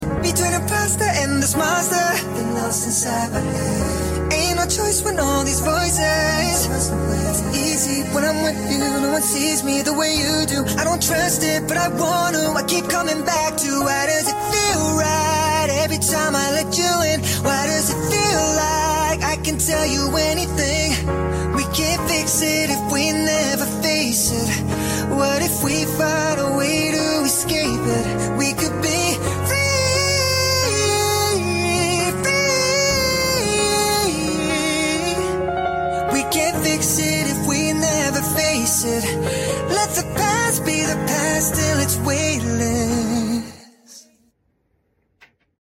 Singing Voice